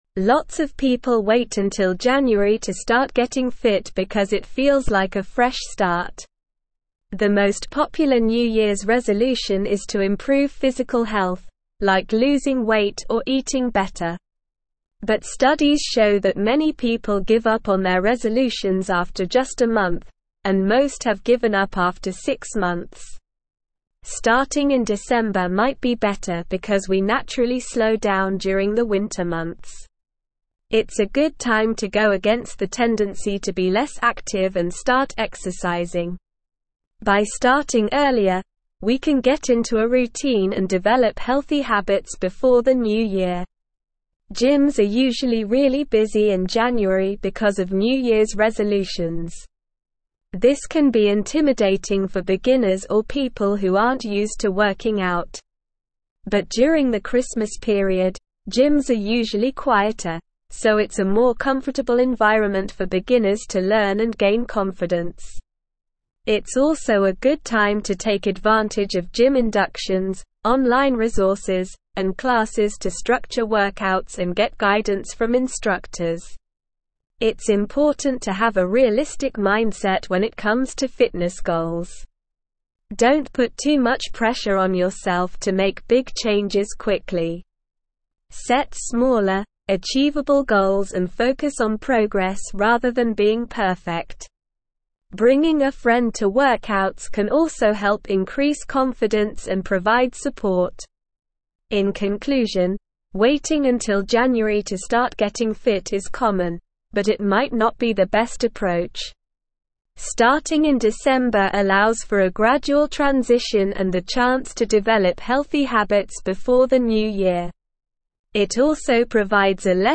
Slow
English-Newsroom-Upper-Intermediate-SLOW-Reading-Starting-Your-Fitness-Journey-Why-December-is-Ideal.mp3